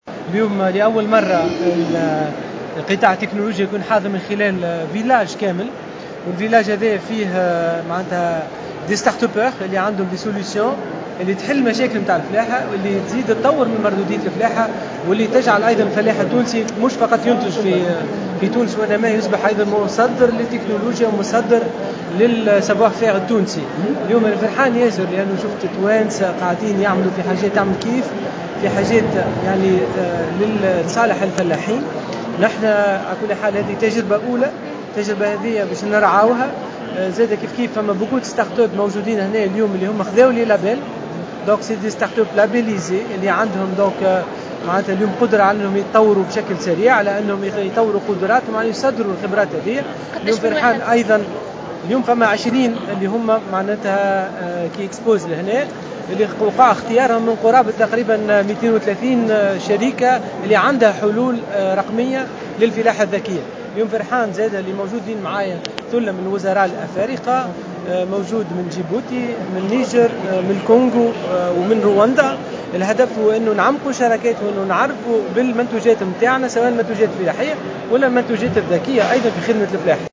تواصلت اليوم الأربعاء فعاليات الدورة 14 للصالون الدولي للفلاحة والآلات الفلاحية والصيد البحري " سياماب 2019، التي تُنظم بقصر المعارض بالكرم من 24 الى 29 سبتمبر 2019.
و خلال حضوره اليوم، أكدّ وزير تكنولوجيات الاتصال والاقتصاد الرقمي "أنور معروف"، أنّ الصالون يُعدّ فرصة لاكتشاف ما توّصل إليه الفلاّح التونسي من تطوّر على مستوى التكنولوجيا في المجال الفلاحي، مشيرا إلى أنّ الفلاح التونسي اليوم أصبح مُصدّرا للتكنولوجيا و اكتسب مهارة تطوير قدراته و تصديرها، إضافة إلى المساهمة بشكل فعّال في تطوير مردودية الفلاحة التونسية من خلال استعماله أحدث التقنيات و حصول عدد من الكفاءات الشابة على براءة اختراع بعض المعدّات الفلاحية المتطوّرة و في مجال الفلاحة الذكية.